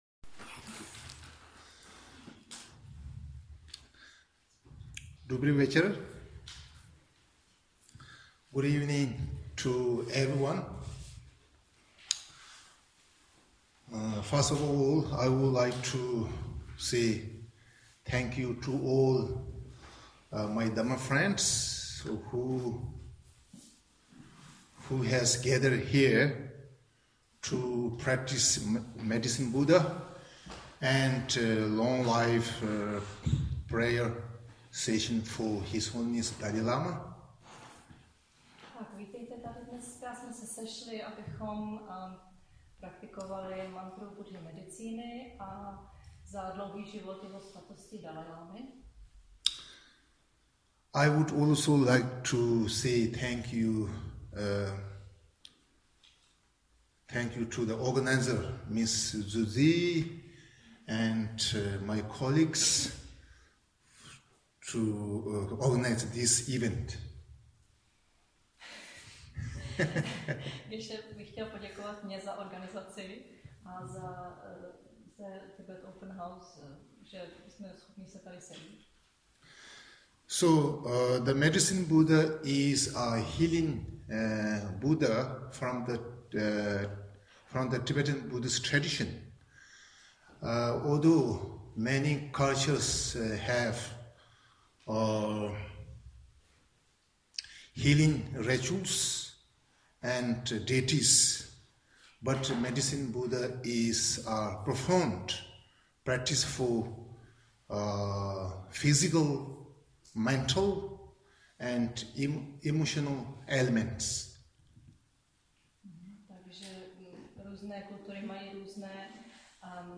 Meditace s mantrou Buddhy medicíny
Obohaceni tímto chápáním jsme se pustili do vedené meditace na Buddhu medicíny a jeho léčivou moc a recitovali při tom mantru Buddhy medicíny. Sezení končilo recitací mantry ÓM MANI PADMÉ HÚM a modlitbou za dlouhý život Jeho Svatosti dalajlámy.